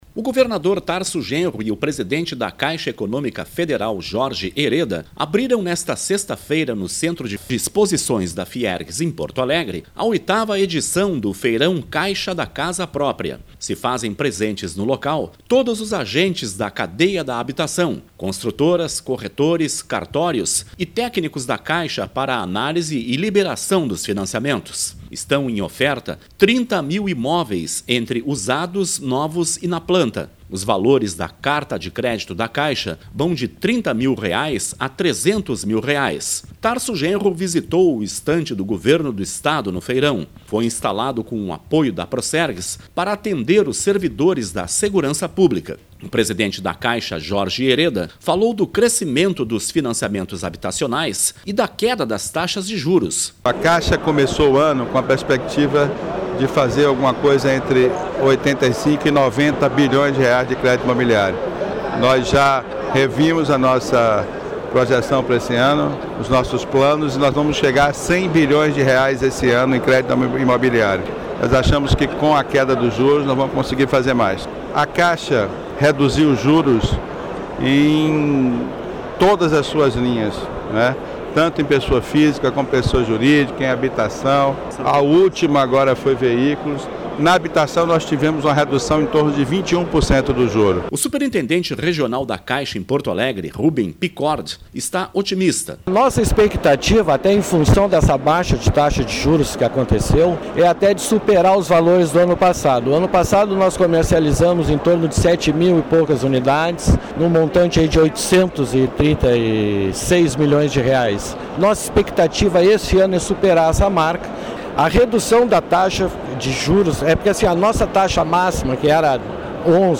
O governador Tarso Genro e o presidente da Caixa Econômica Federal, Jorge Hereda, abriram nesta sexta-feira (25), no Centro de Exposições da Fiergs, em Porto Alegre, a 8ª edição do Feirão Caixa da Casa Própria.